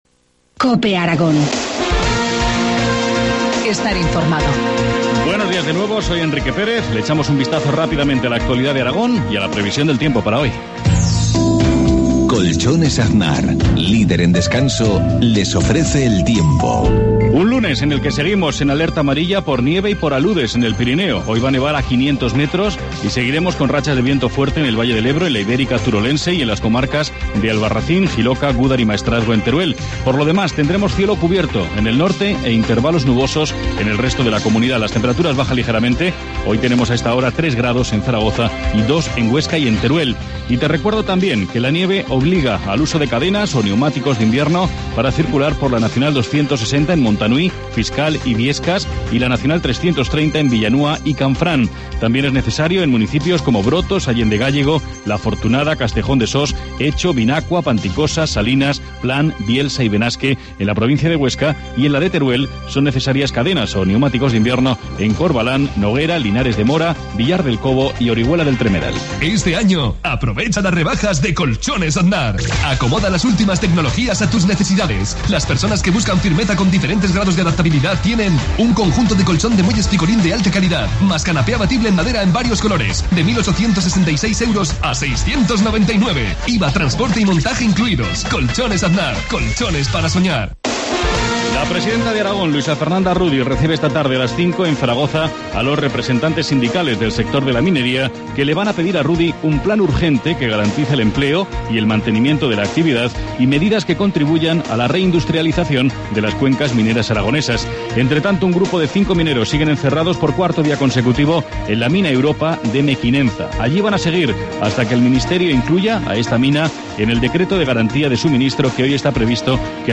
Informativo matinal, lunes 11 de febrero, 7.53 horas